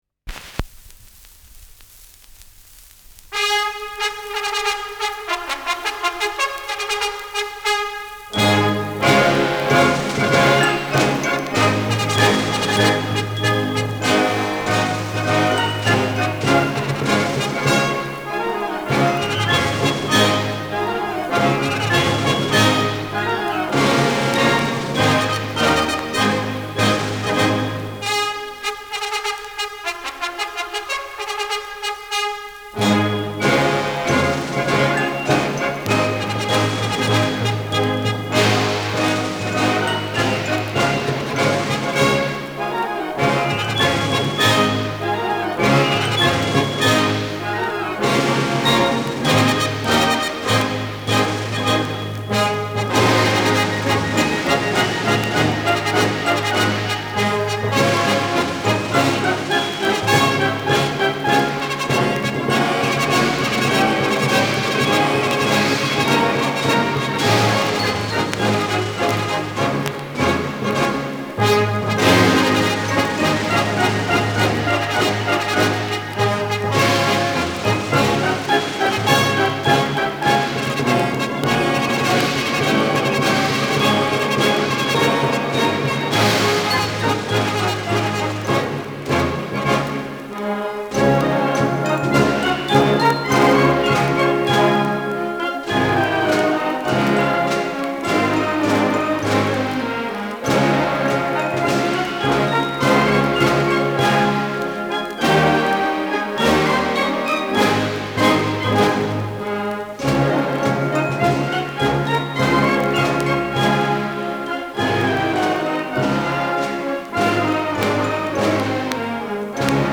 Schellackplatte
Tonrille: Berieb Durchgehend Leicht